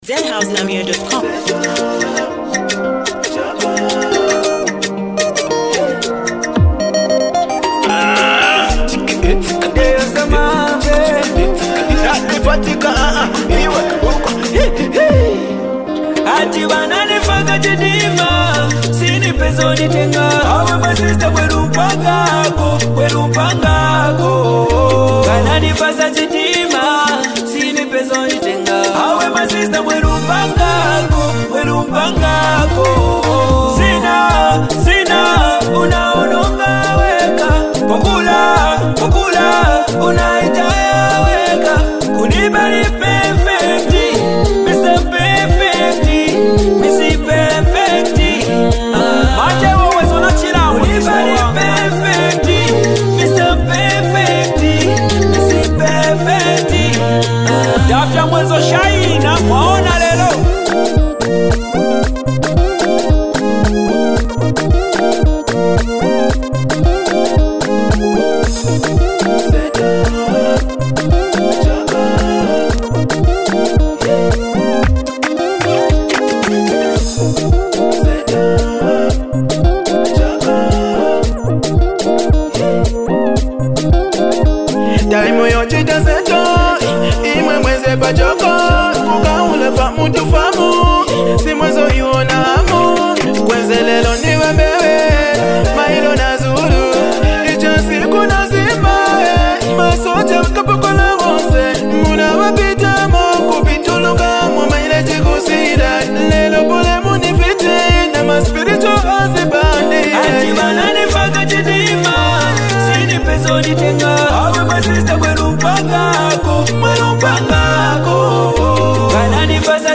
a powerful banger